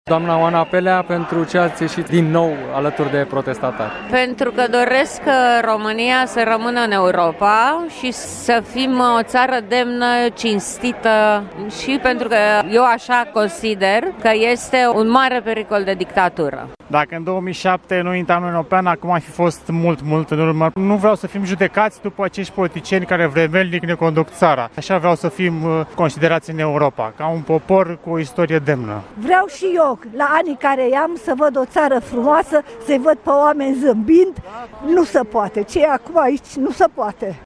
În timpul ceremoniei, în apropiere de Ateneu, câteva sute de persoane au participat la un miting pro-european, cu steaguri ale României şi ale Uniunii Europene. Ei au vrut să le transmită liderilor europeni prezenţi la Bucureşti că românii cred în valorile care stau la baza Uniunii. Sub sloganul „Vrem Europa, nu dictatură!” oamenii au strigat şi împotriva Guvernului şi a partidelor la putere.
VOX-ATENEU.mp3